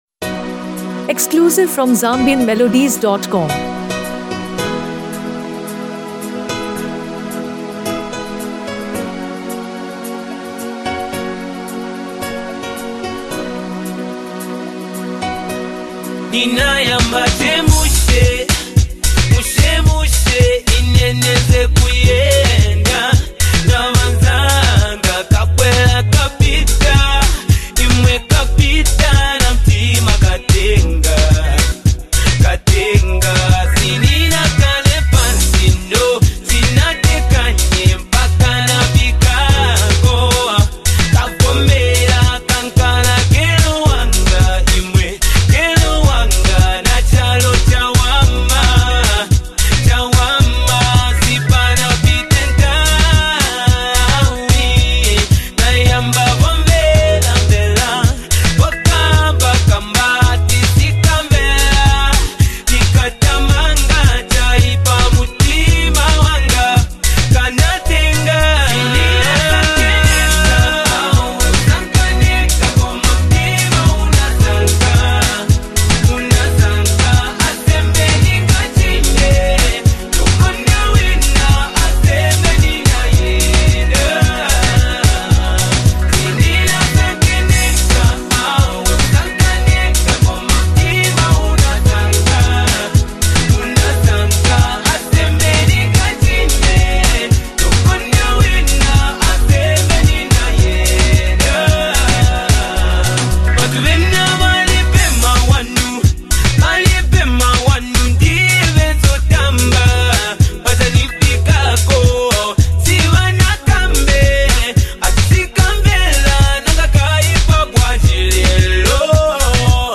a poignant and soulful song
rich and expressive voice